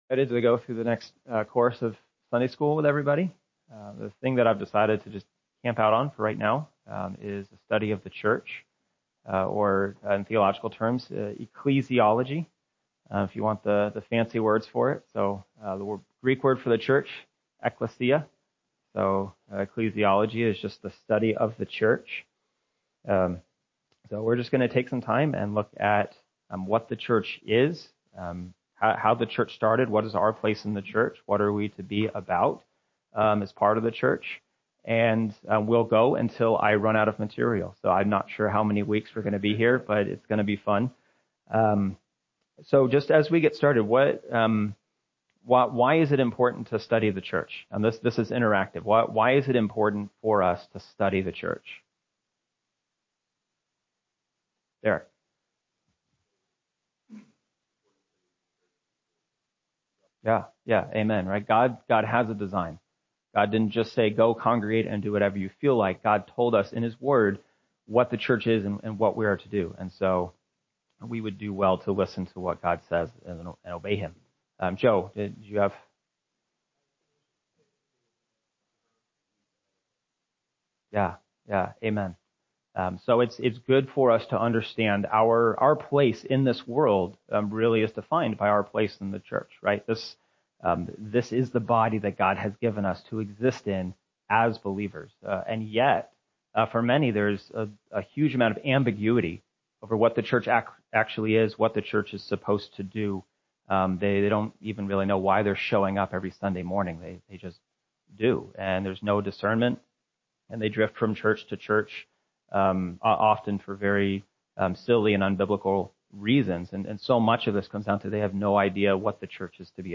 From Series: "Adult Sunday School"